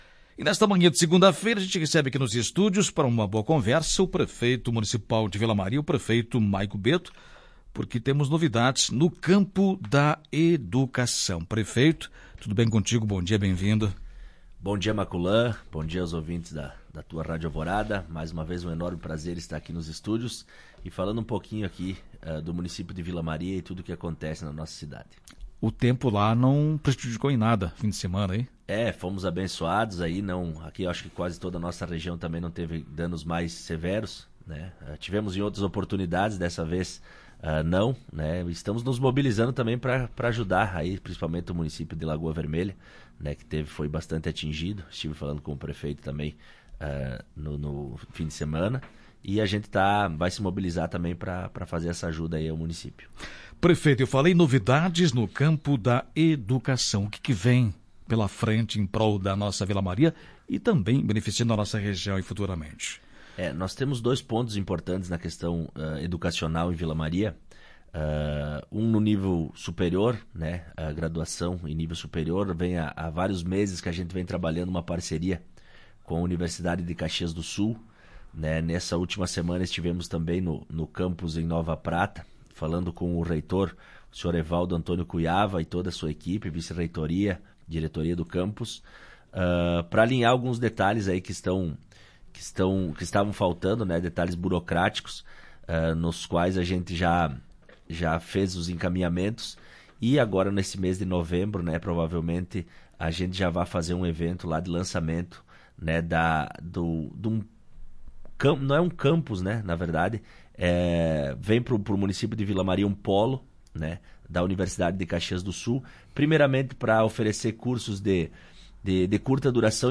O Município de Vila Maria traz para o ano de 2020 duas novidades para a área da educação, uma para o ensino superior e outra para o ensino infantil. Em conversa com a Tua Rádio Alvorada o prefeito do município, Maico Betto, falou sobre os projetos.